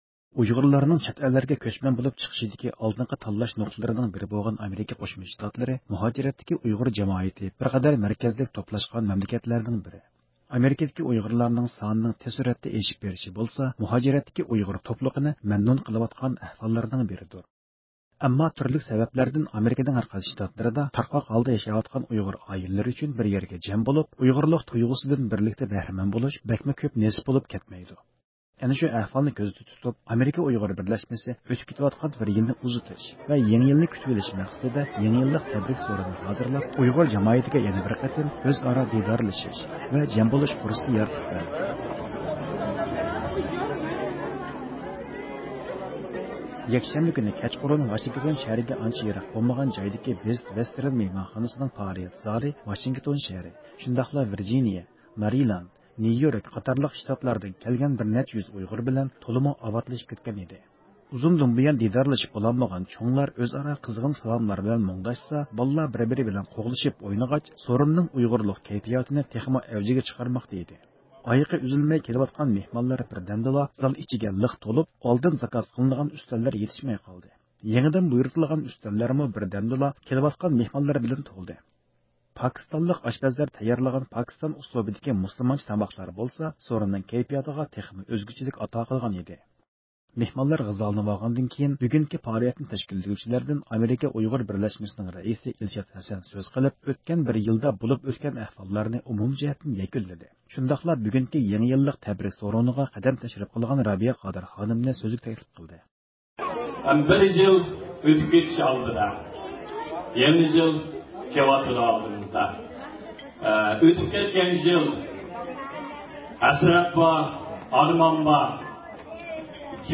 31-دېكابىر كۈنى ئامېرىكىنىڭ پايتەختى ۋاشىنگتون شەھىرى ۋە يېقىن ئەتراپتىكى شتاتلاردىن كەلگەن ئۇيغۇرلار بىر يەرگە توپلىنىپ كېلىۋاتقان يېڭى يىلنى بىرلىكتە كۈتۈۋالدى.